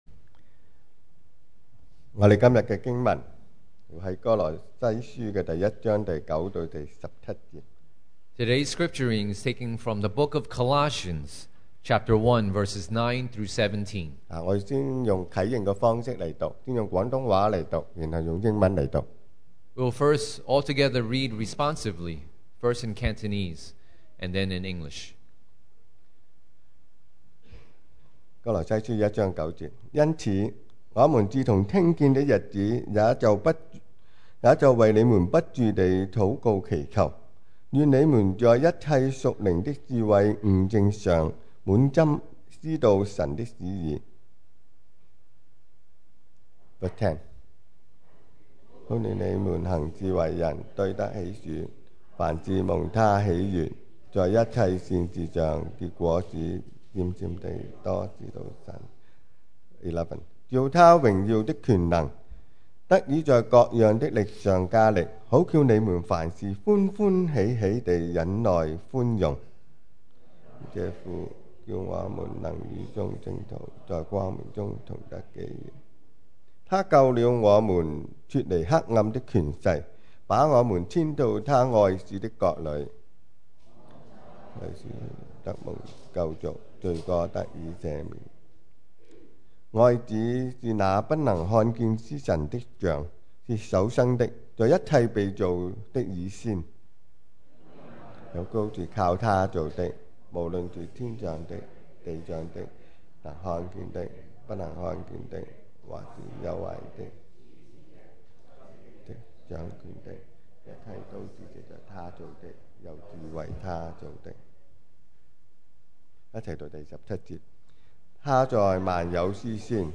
2020 sermon audios
Service Type: Sunday Morning